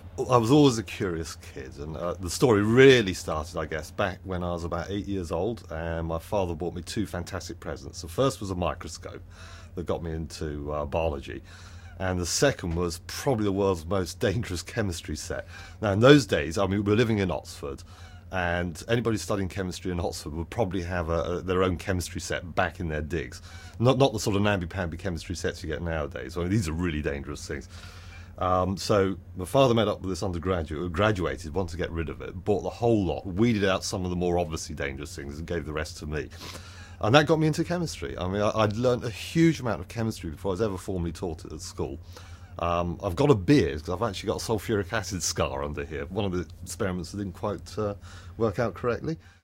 Interviewee: Alec Jeffreys DNAi Location: Timeline>1980s